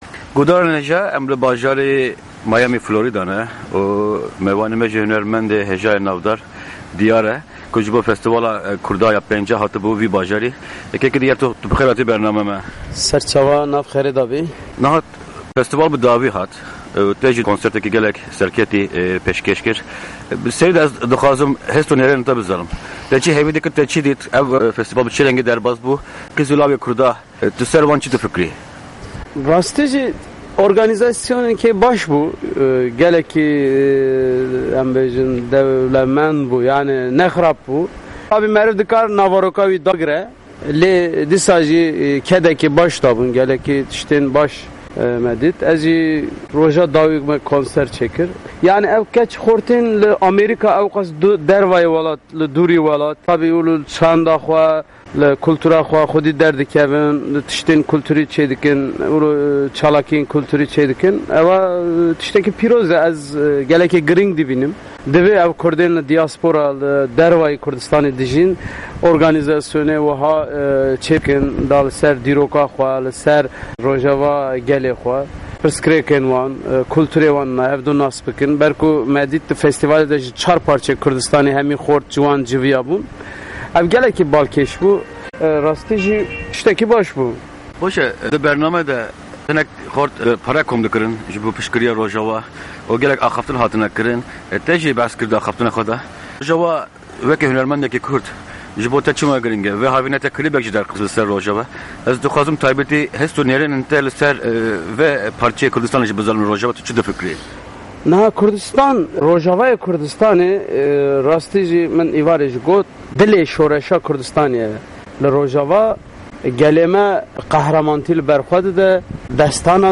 Hevpeyvîneke Taybet